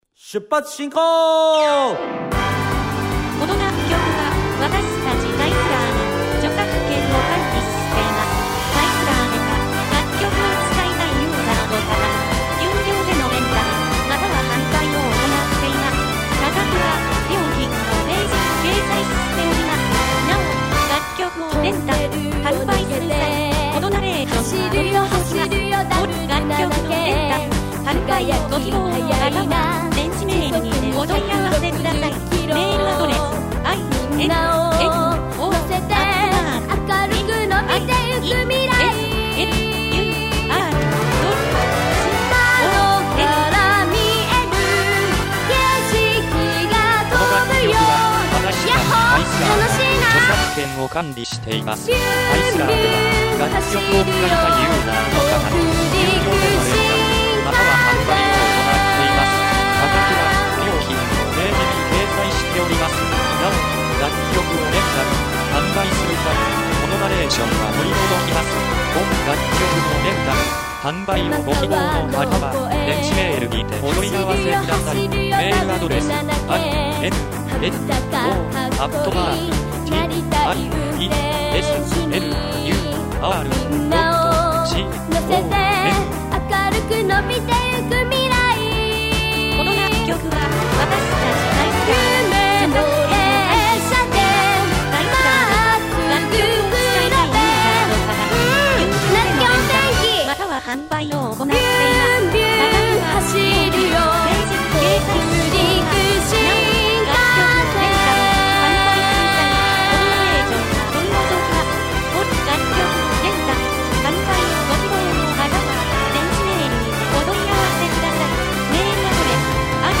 ◆アップテンポ系ボーカル曲